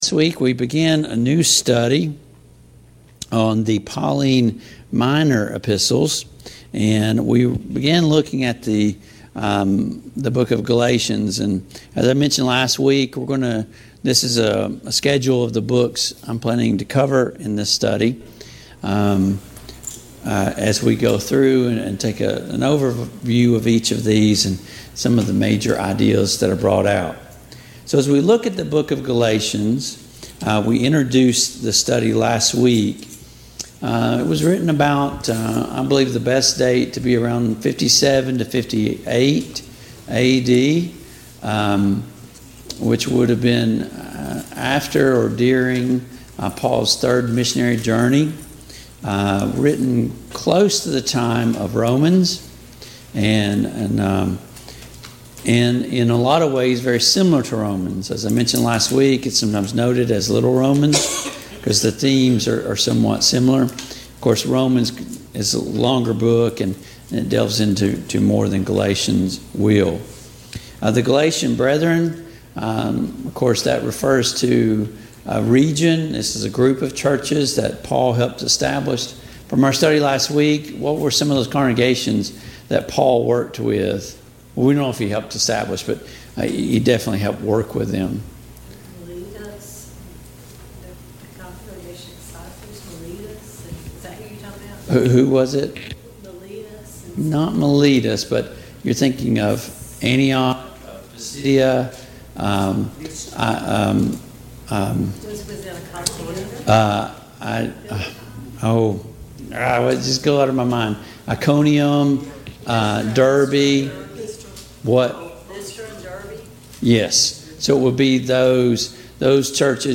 Study of Paul's Minor Epistles Service Type: Mid-Week Bible Study Download Files Notes « Seek First the Kingdom!